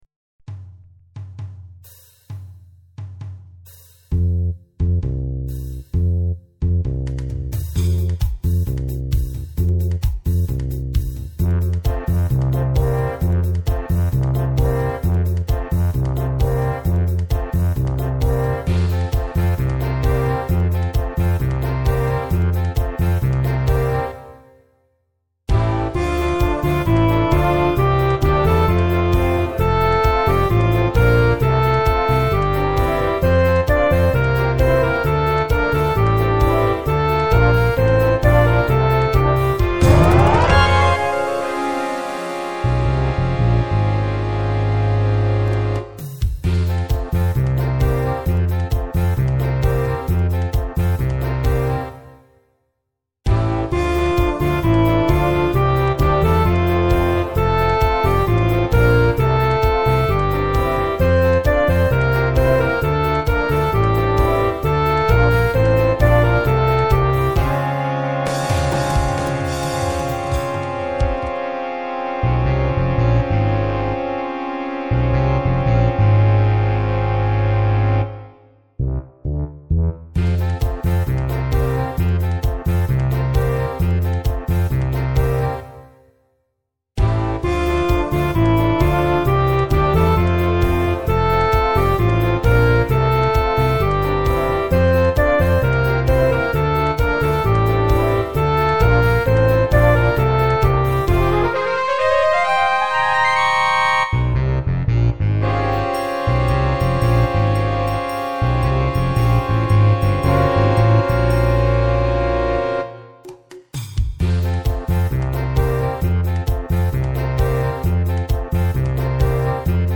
Badisches Volkslied Arrangeur
Kategorie: Humor